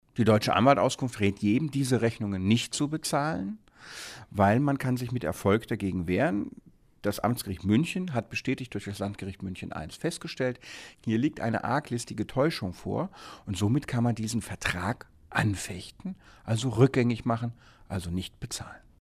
O-Ton: Vorsicht vor Rechnungen von Adressverzeichnissen